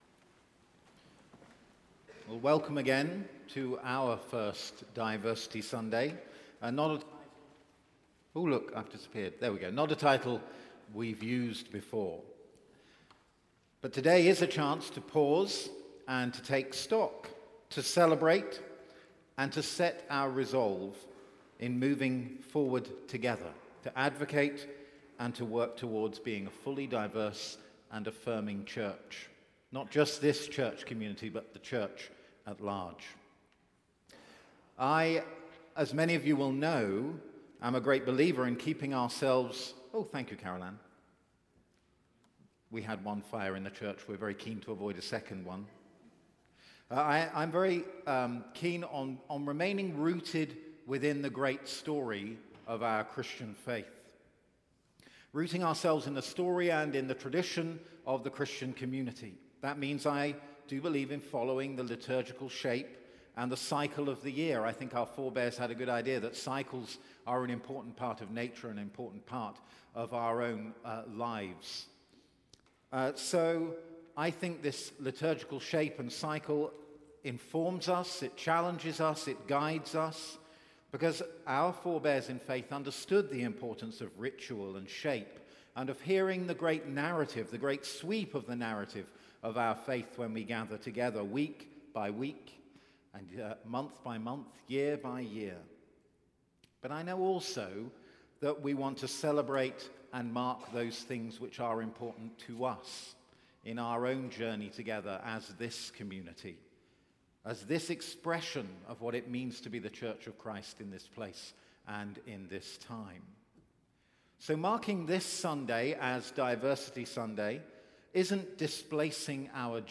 Evensong Reflection